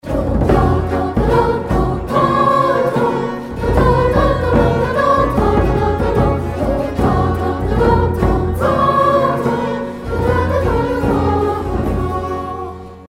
Le jour du 1er septembre, en classe, nous vous invitons vous et vos élèves à écouter, chanter, rythmer, vous mouvoir ou vous exprimer corporellement et inventer des paroles sur une musique de Marc-Antoine Charpentier (1643-1704), un compositeur et chanteur français de musique baroque.
Interprétation : Le Consort et la maîtrise populaire de l'Opéra Comique,
Le prélude est construit sur la base d'une forme rondo, c'est à dire sur l'alternance couplet/refrain.